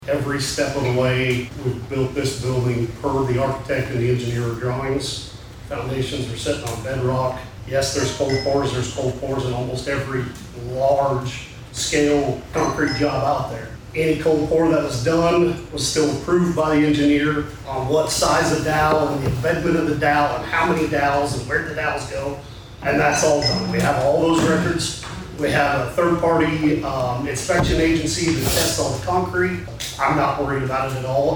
Things Get Testy at Osage Co Commissioner Meeting
the person overseeing the entire operation.